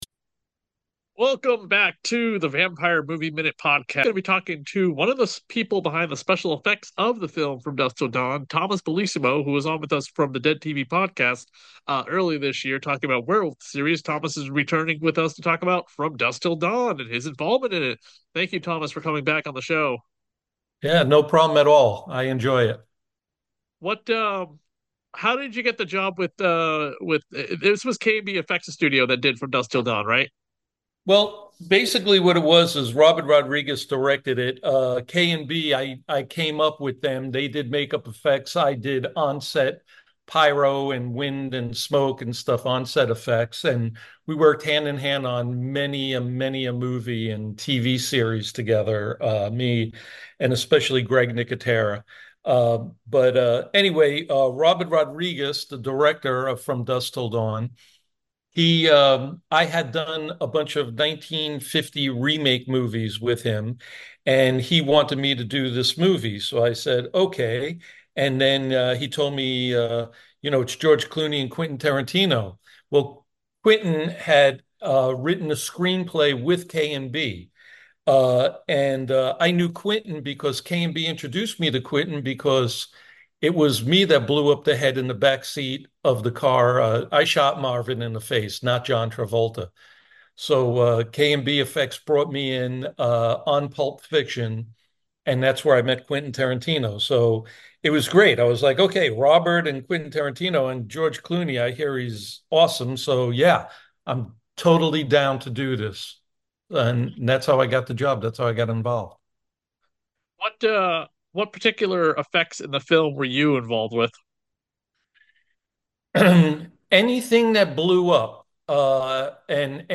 FDTD-Interview.mp3